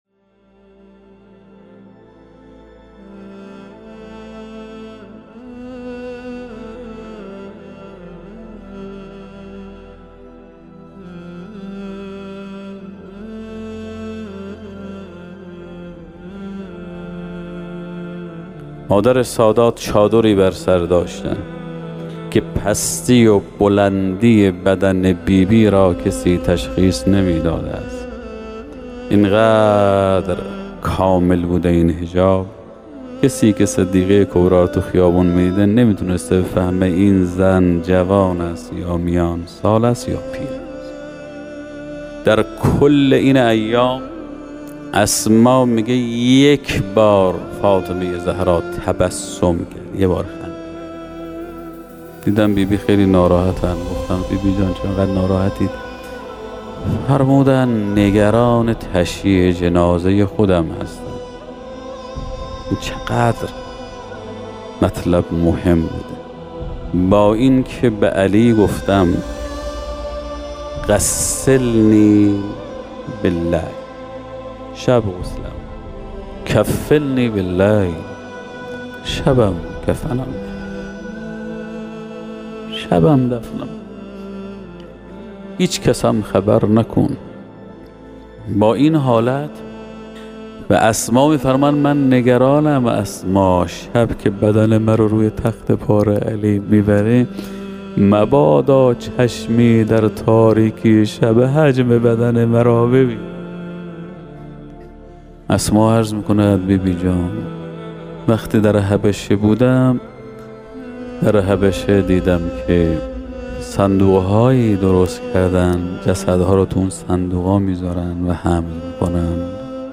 ایکنا به مناسبت ایام سوگواری شهادت دخت گرامی آخرین پیام‌آور نور و رحمت، مجموعه‌ای از سخنرانی اساتید اخلاق کشور درباره شهادت ام ابیها (س) با عنوان «ذکر خیر ماه» منتشر می‌کند.